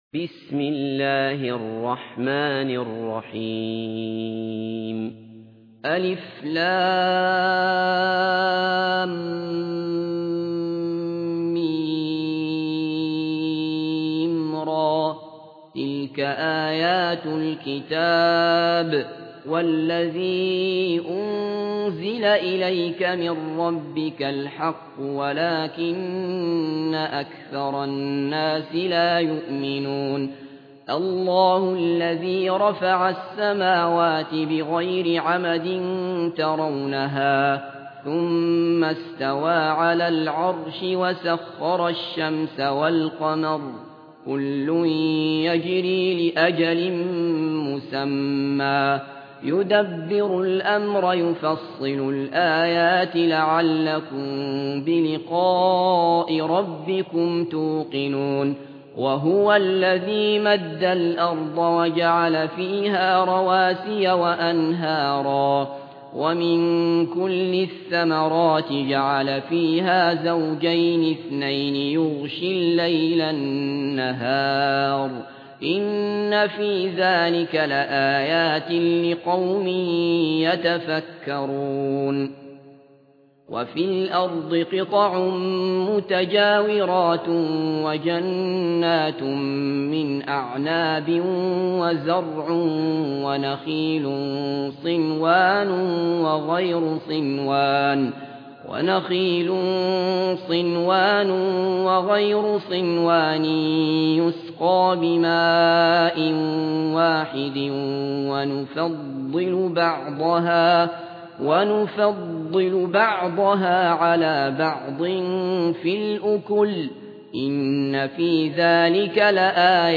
قرآن - قاری عبد الله بصفر